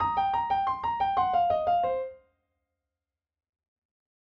So here’s a blues run in the key of C. It sounds great in a solo, and it spans over more than two octaves.
Blues Run for Piano Lick 1
piano-blues-run-part1.mp3